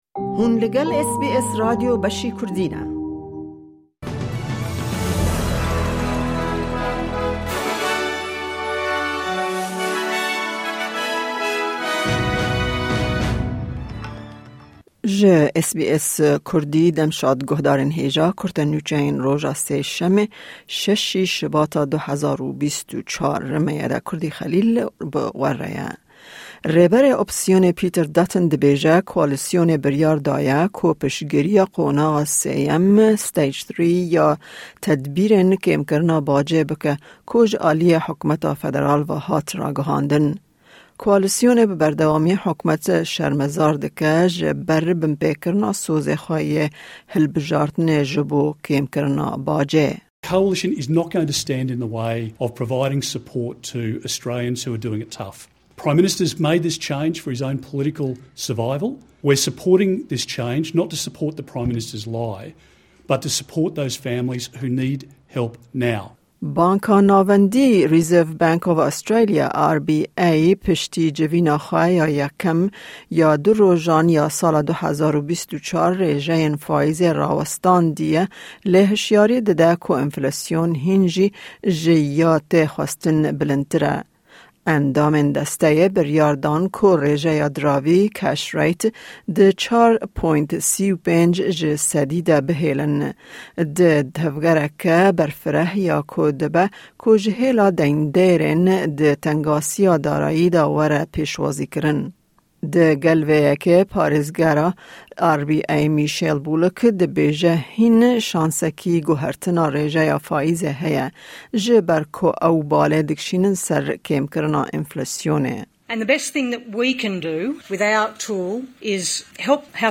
Kurte Nûçeyên roja Sêşemê 6î Şubata 2024